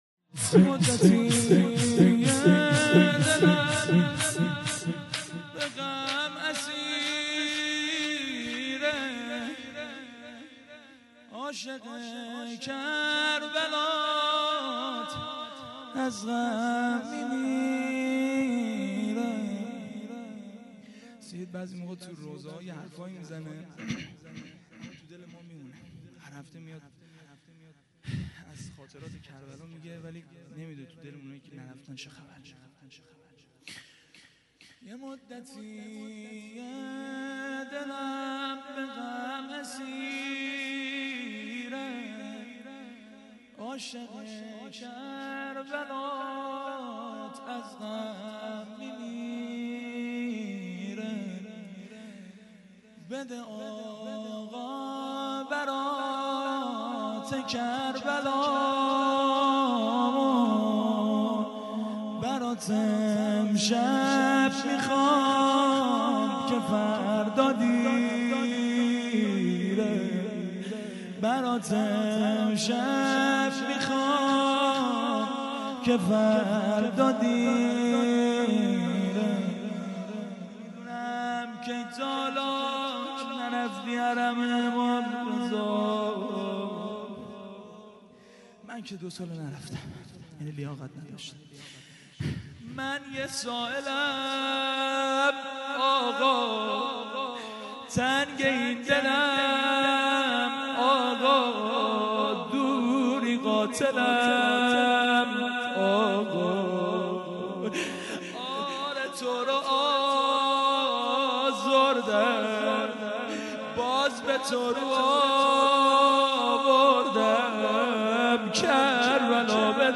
روضه-پایانی-07.mp3